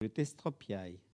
Collectif atelier de patois
Catégorie Locution